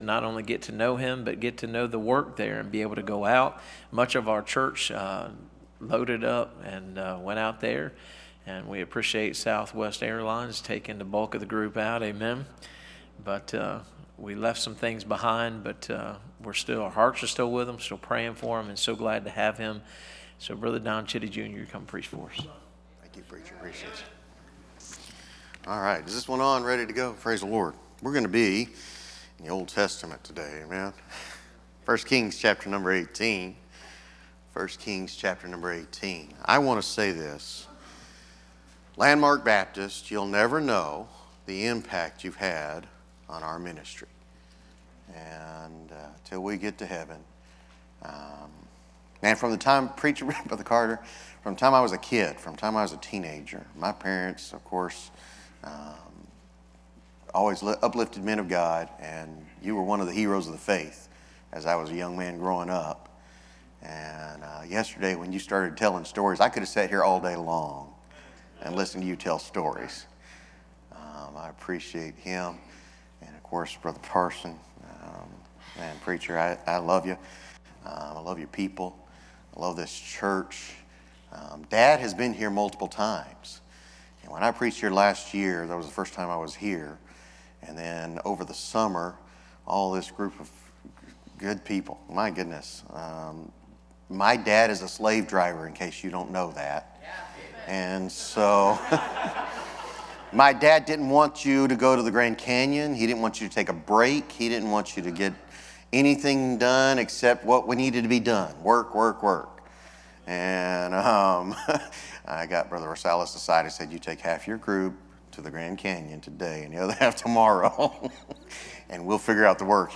Series: 2025 Bible Conference
Preacher